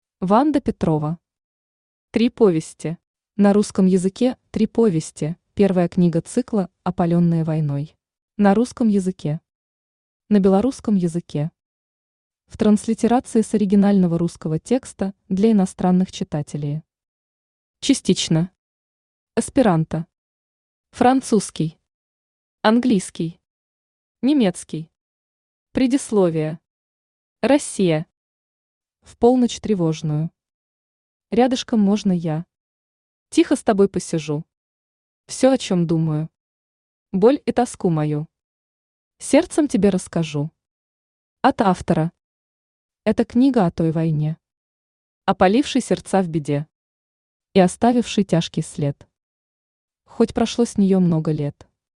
Аудиокнига Три повести | Библиотека аудиокниг
Aудиокнига Три повести Автор Ванда Михайловна Петрова Читает аудиокнигу Авточтец ЛитРес.